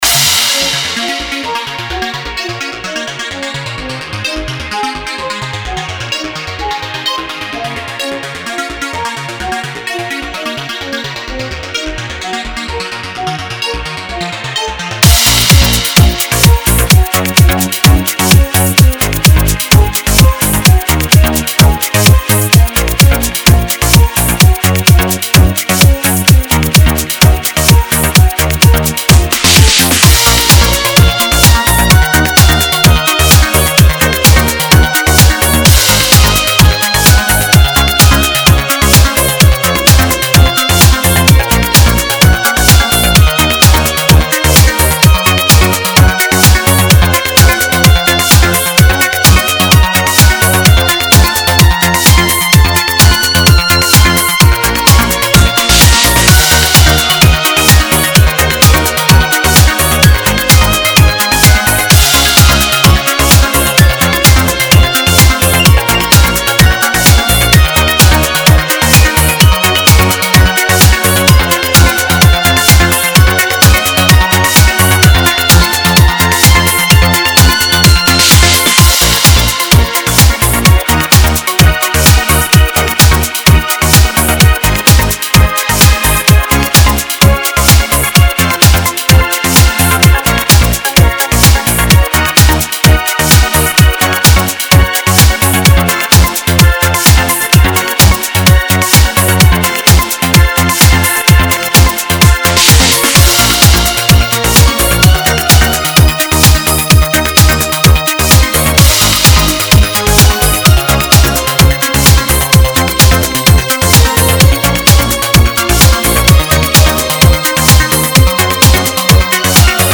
си бемоль минор